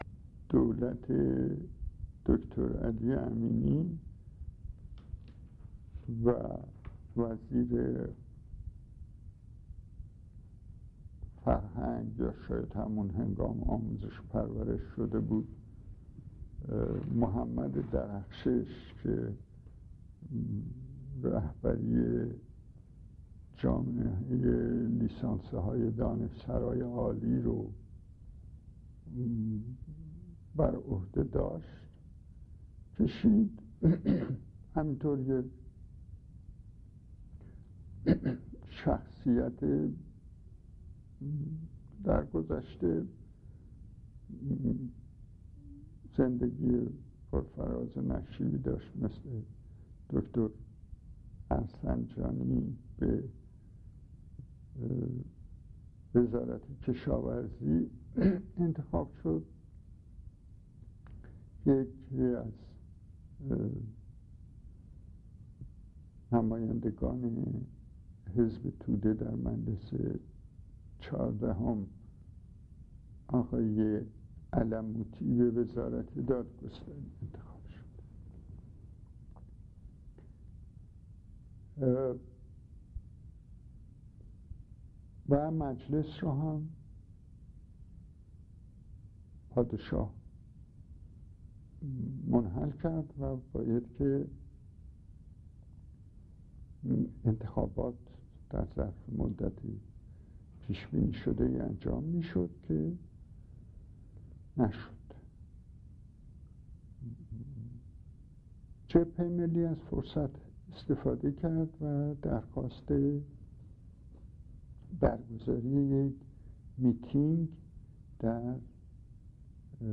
فرازهایی از گفتگوی داریوش فروهر با مرکز اسناد ملی ایران